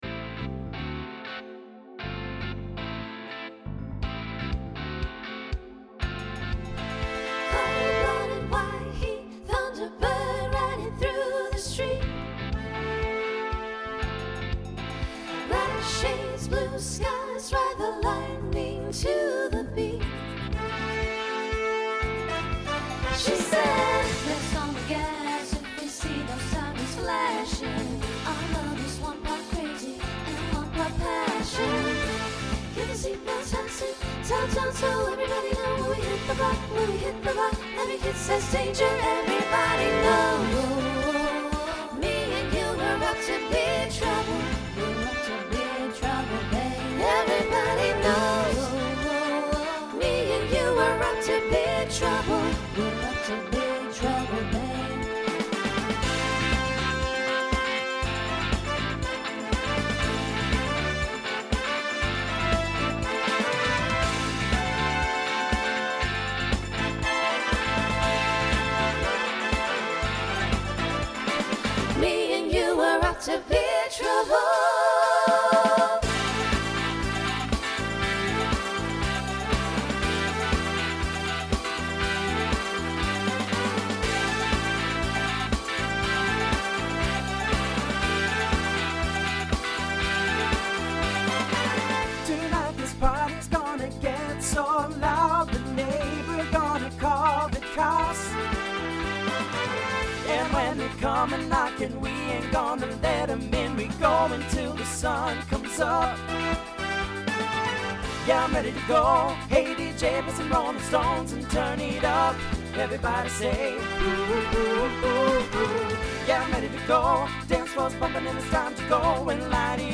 SSA/TTB
Voicing Mixed
Genre Pop/Dance